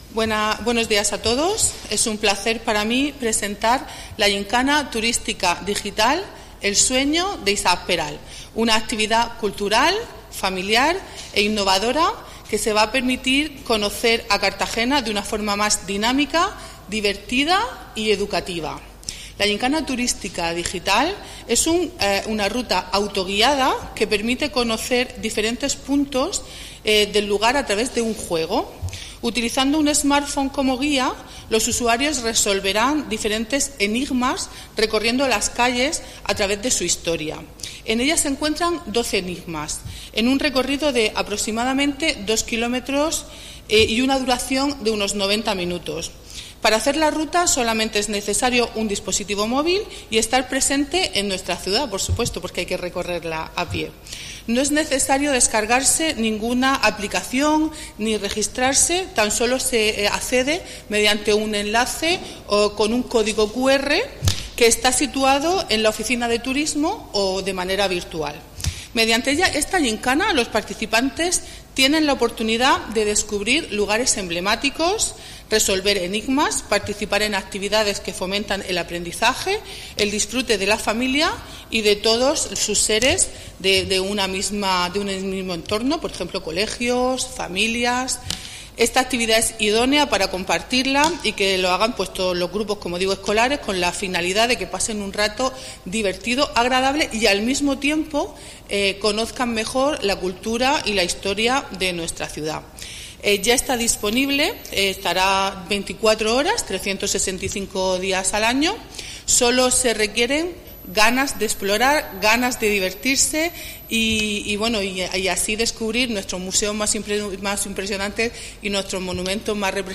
Presentación de la gymkana turística digital 'El sueño de Isaac Peral'